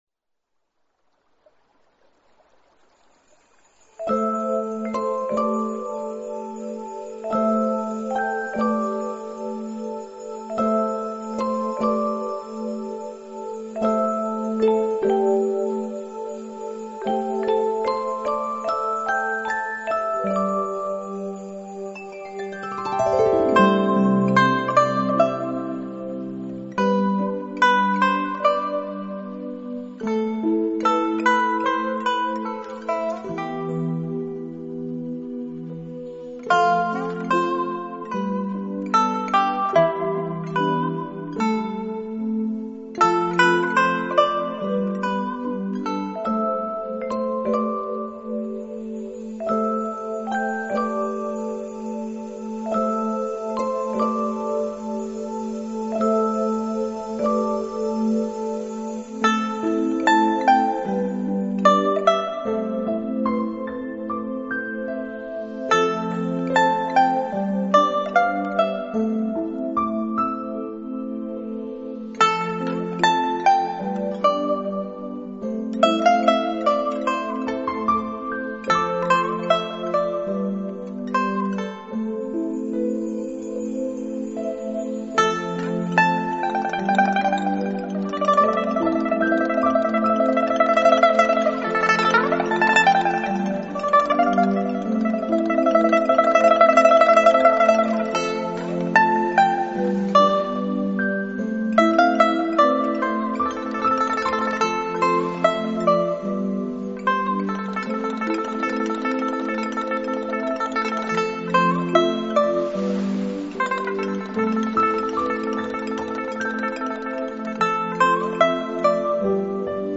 一种祥和平静的安逸感随着清亮的琵琶与吉他声蔓延开来